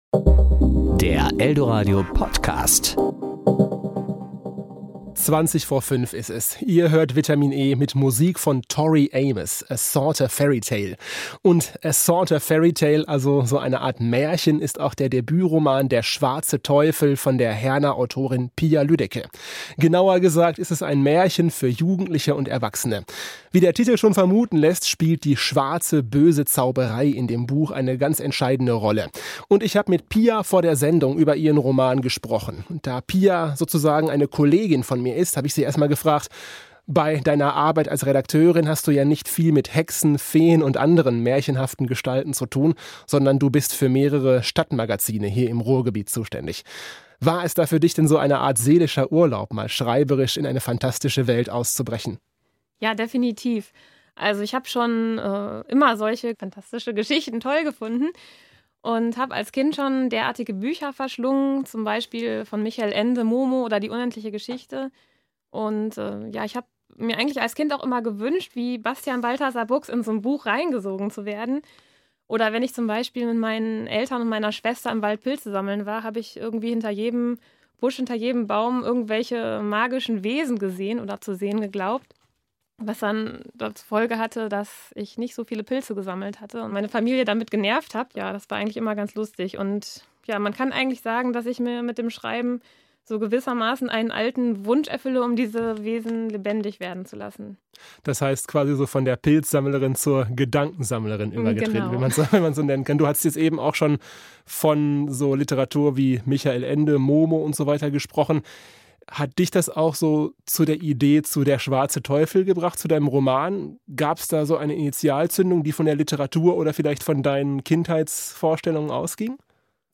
Serie: Interview Sendung: Vitamin e*